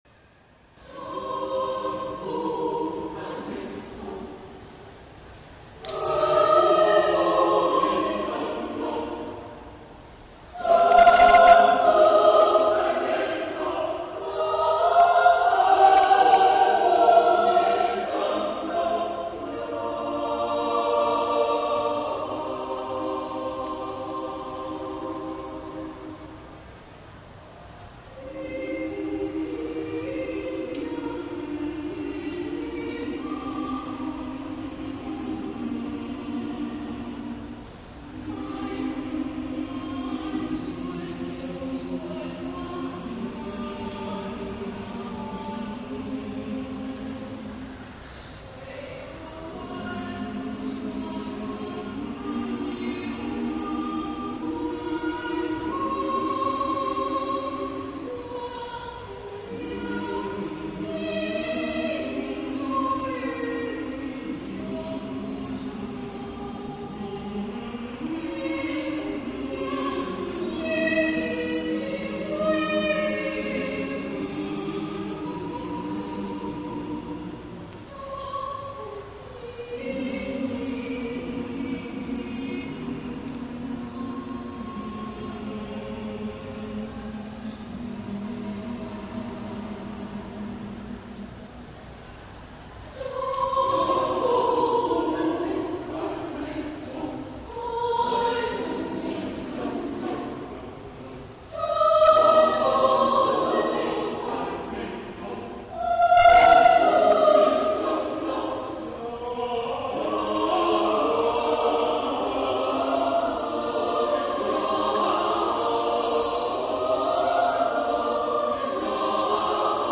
除定時練習外，每年也騁請聲樂 家來團作培訓，是本澳一支充滿活力的 合唱團。